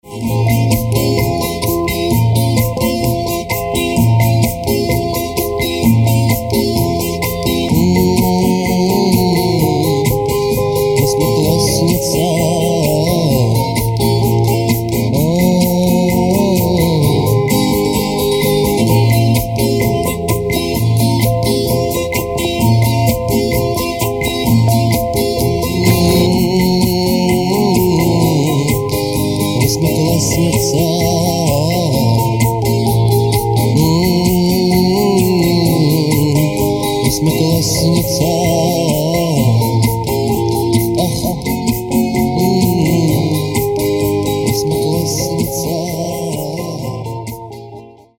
• Качество: 320, Stereo
русский рок